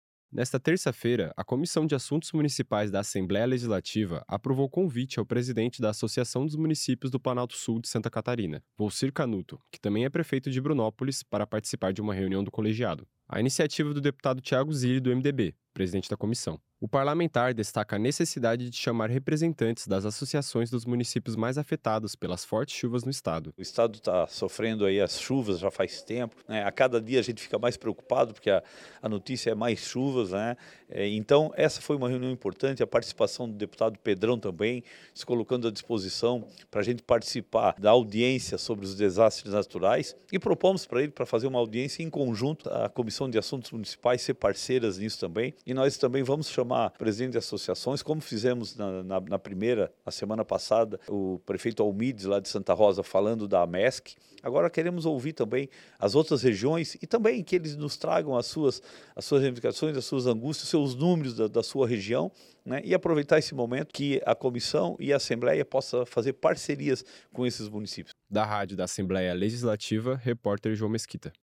Entrevista com:
- deputado Tiago Zilli (MDB), presidente da comissão e autor do requerimento.